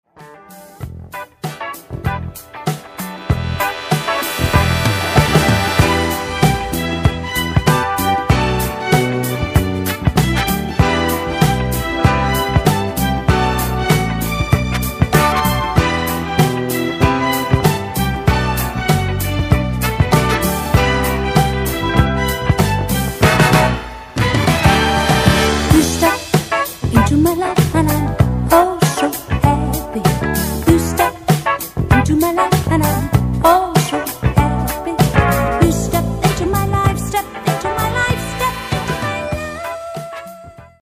ジャパニーズ・シティ・ポップ・コレクション